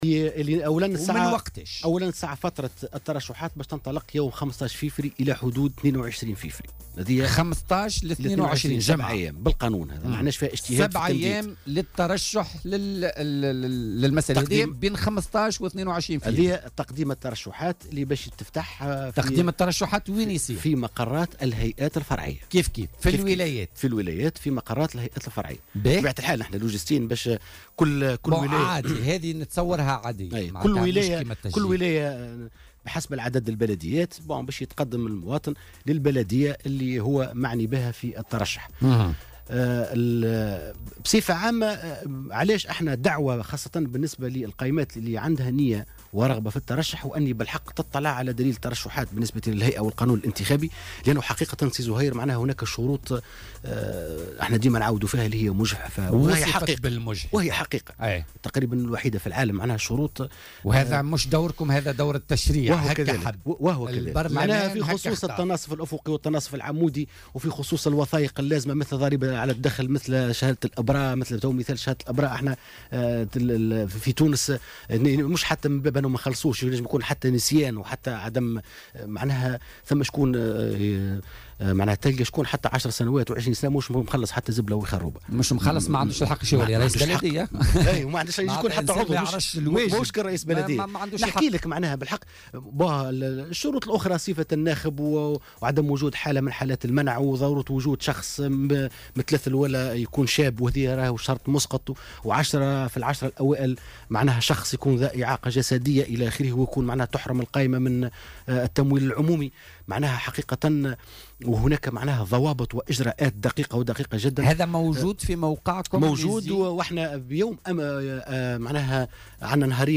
وأوضح ضيف "بوليتيكا" أن موعد التّرشح سيكون من 15 والى غاية 22 فيفري 2018 في مقرات الهيئات الفرعية بعد غلق باب التسجيل يوم 6 جانفي الحالي.